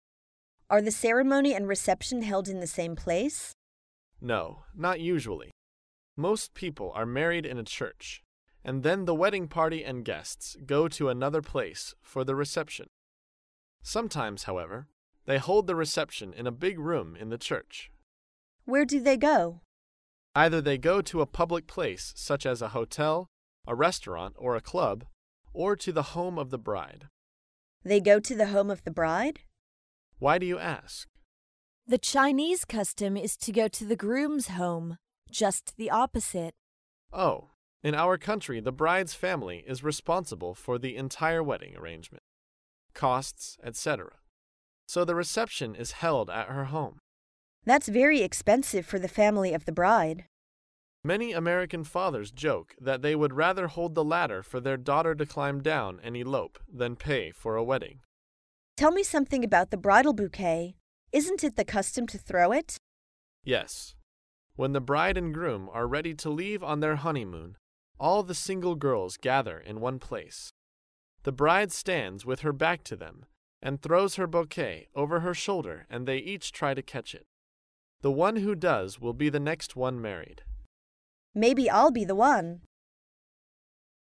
在线英语听力室高频英语口语对话 第355期:婚礼习俗的听力文件下载,《高频英语口语对话》栏目包含了日常生活中经常使用的英语情景对话，是学习英语口语，能够帮助英语爱好者在听英语对话的过程中，积累英语口语习语知识，提高英语听说水平，并通过栏目中的中英文字幕和音频MP3文件，提高英语语感。